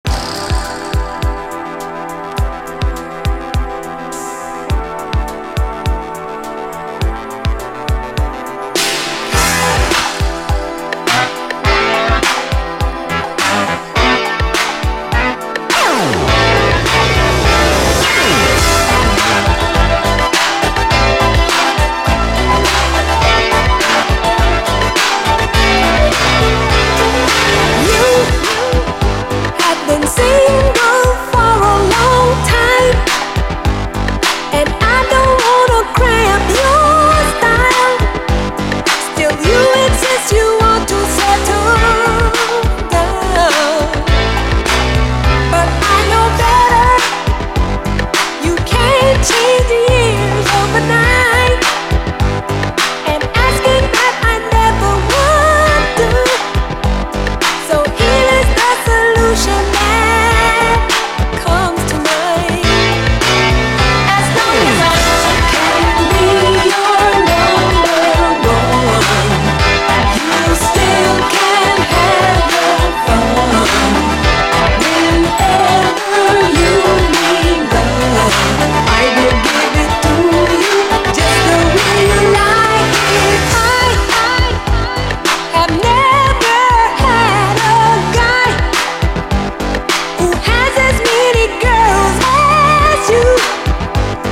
SOUL, 70's～ SOUL, DISCO, 7INCH
80’Sメロウ・シンセ・ブギー・ファンク・クラシック！